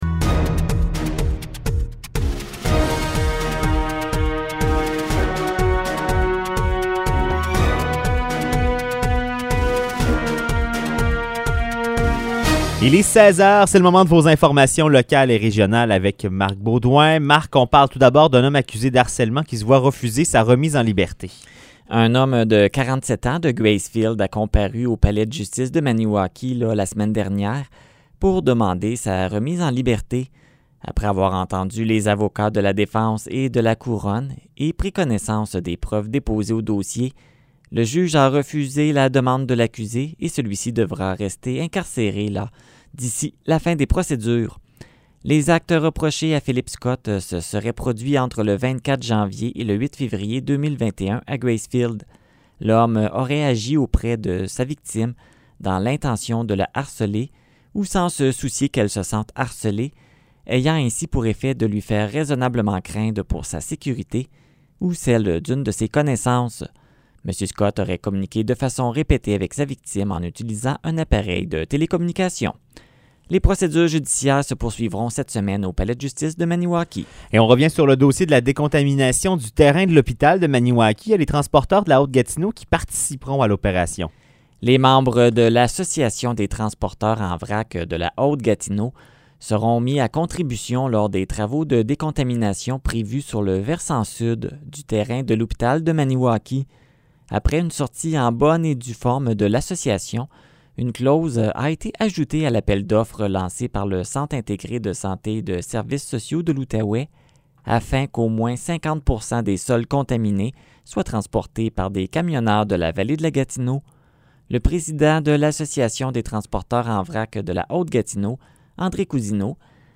Nouvelles locales - 22 février 2021 - 16 h